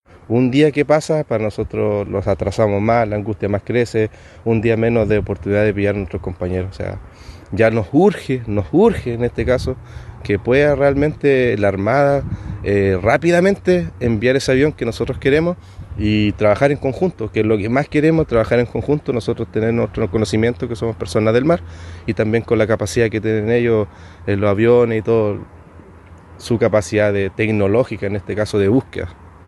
el pescador artesanal